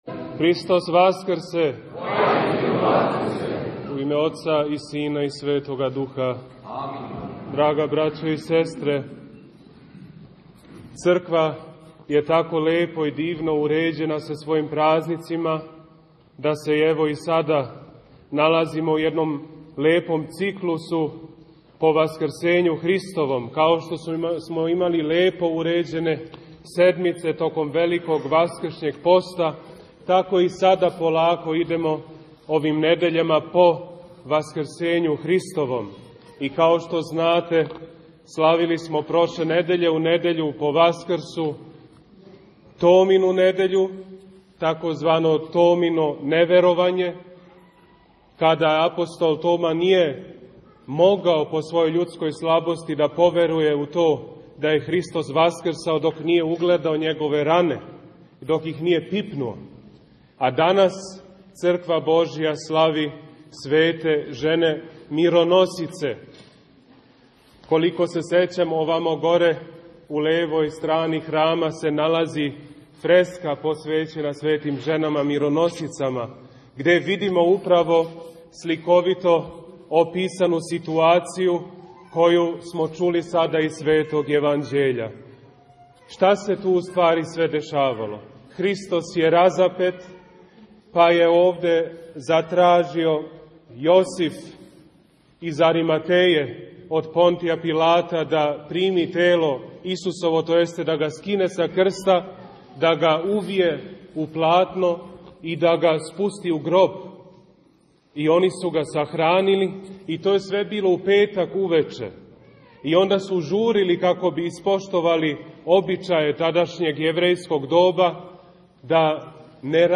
Беседа у Недељу мироносица